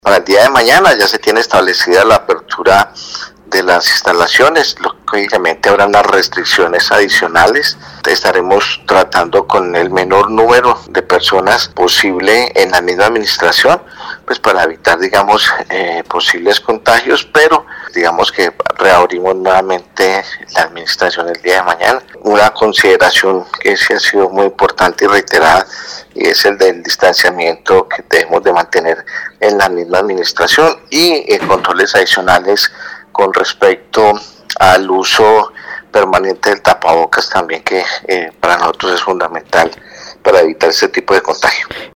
Periodismo Investigativo dialogó con el Alcalde de Armenia Dr. José Manuel Ríos Morales y sobre su salud explicó que: